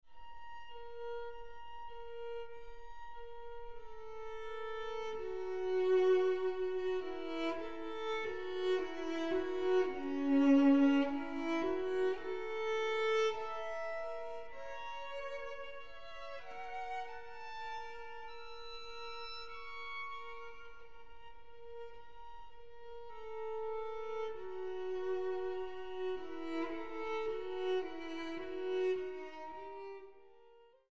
Violin
Cello